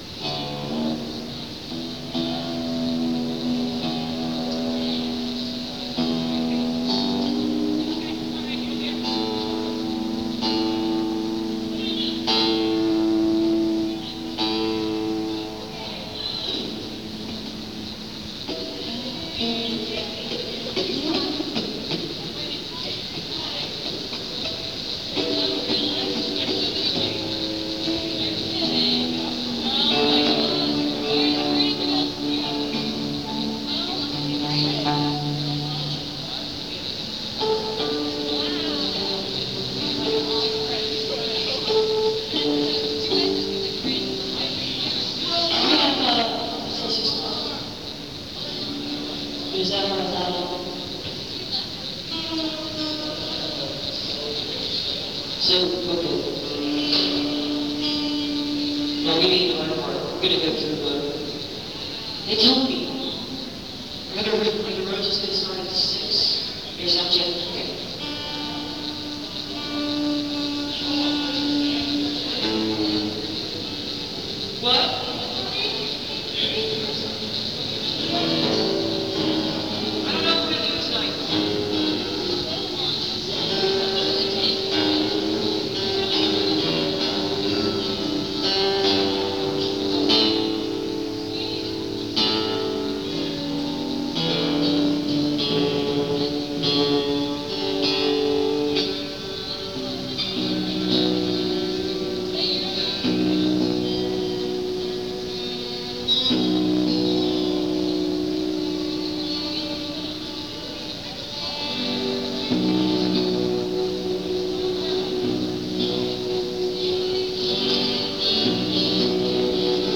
soundcheck